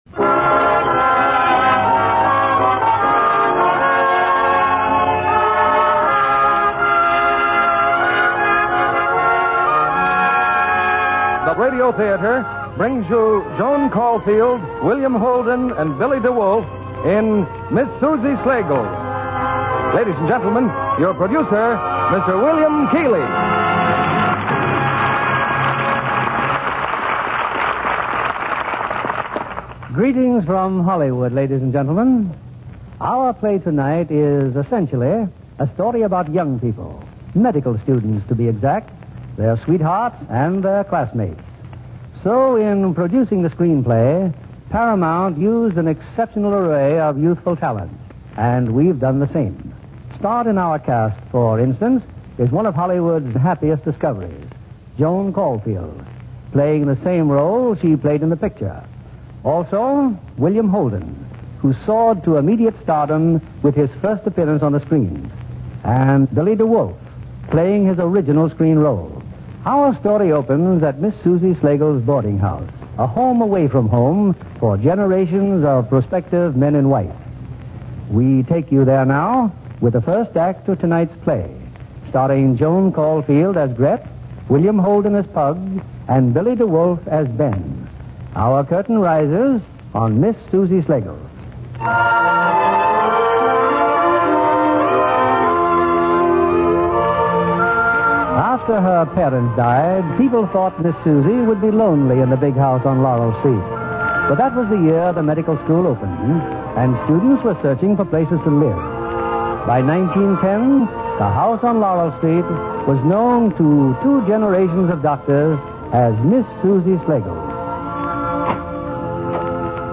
Miss Susie Slagles, starring Joan Caufield, William Holden